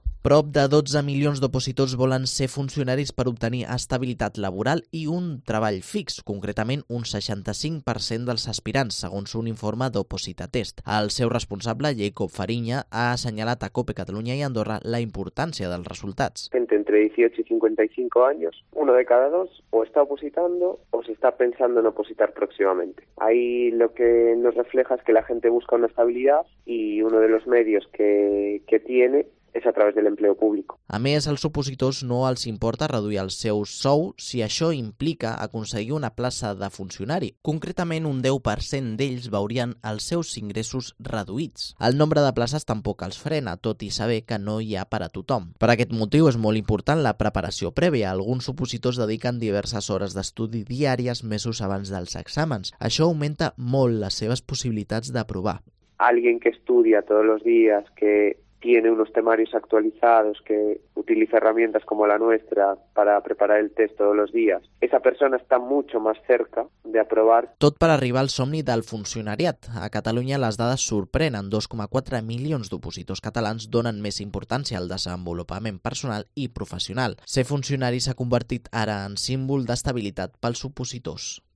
crónica sobre el informe de OpositaTest a cerca de las oposiciones